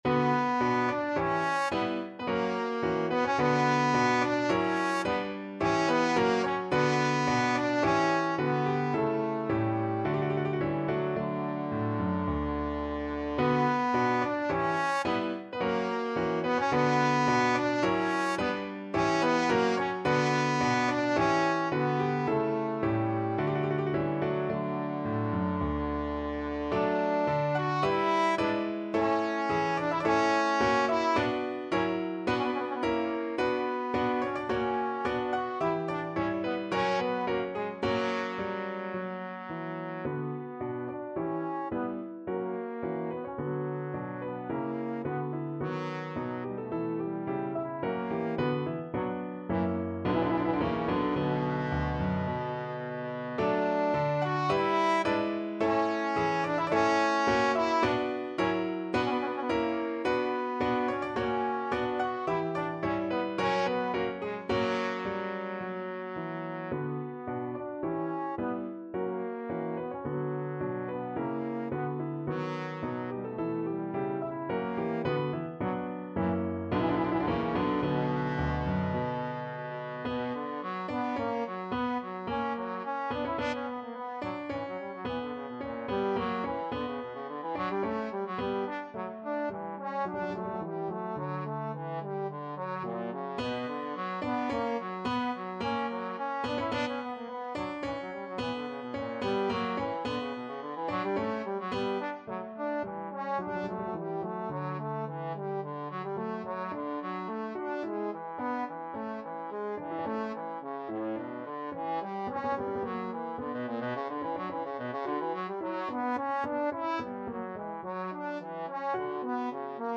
Classical Bach, Johann Sebastian Polonaise & Double, No. 5 from Suite in B minor, BWV 1067 Trombone version
Trombone
B minor (Sounding Pitch) (View more B minor Music for Trombone )
~ = 54 Moderato
A3-F#5
3/4 (View more 3/4 Music)
Classical (View more Classical Trombone Music)
bach_polonaise_double_bwv_1067_TBNE.mp3